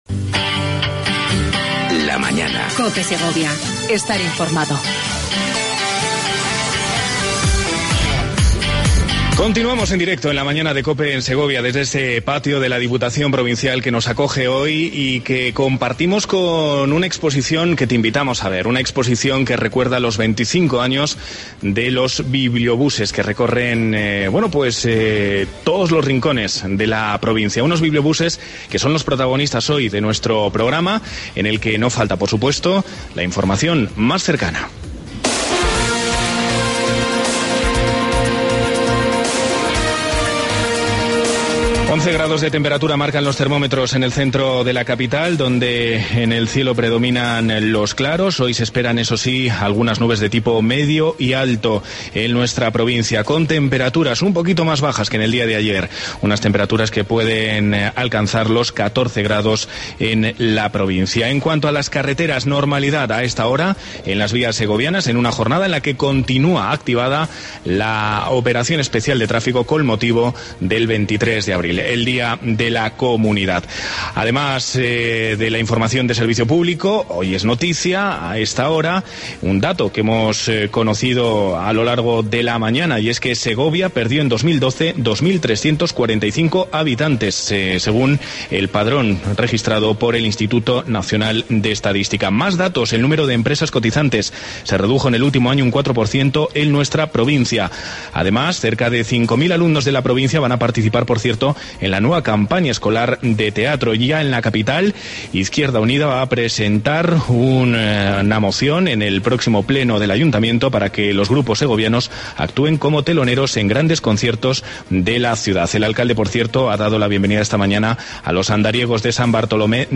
AUDIO: Programa especial desde El Patio del Palacio de La Diputación de Segovia.